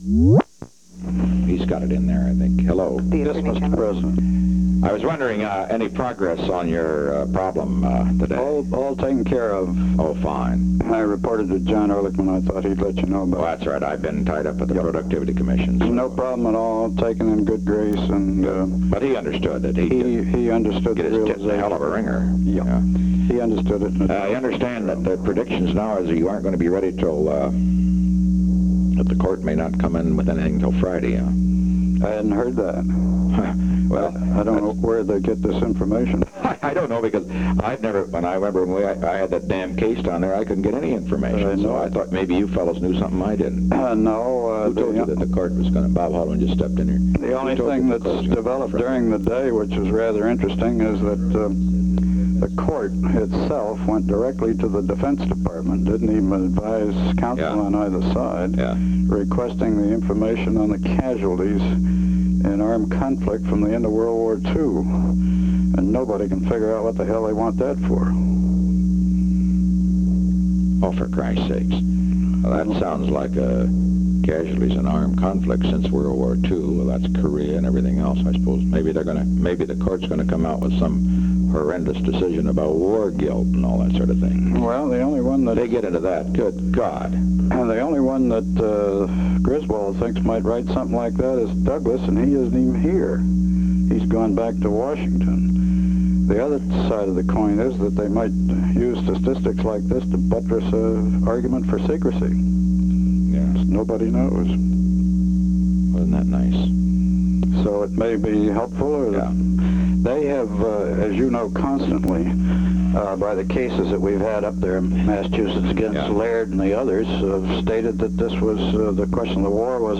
Location: White House Telephone
The President talked with John N. Mitchell; the President spoke to H.R. (“Bob”) Haldeman in